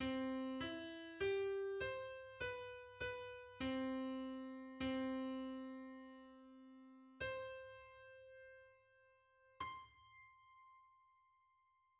Music notation
{ \time 4/4 \clef treble c'4 e' g' c'' b' b' c'2 c'1 c'' c''' }